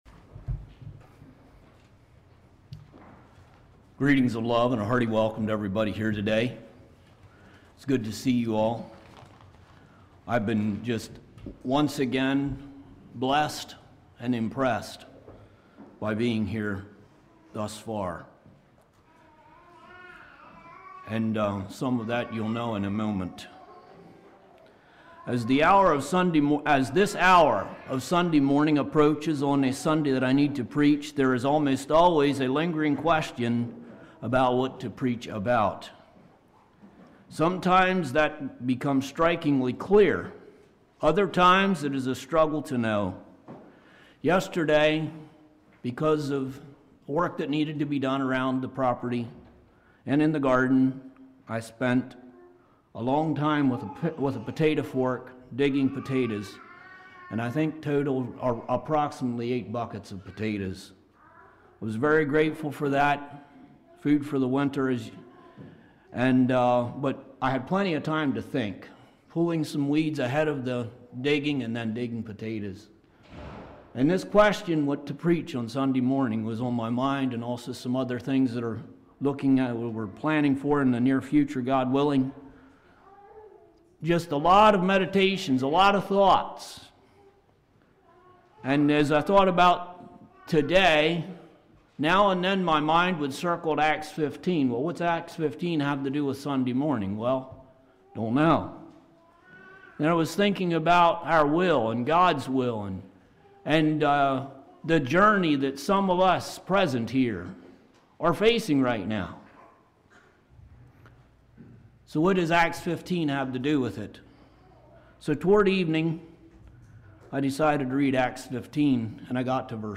Play Now Download to Device God Made Choice Among Us Congregation: Providence Speaker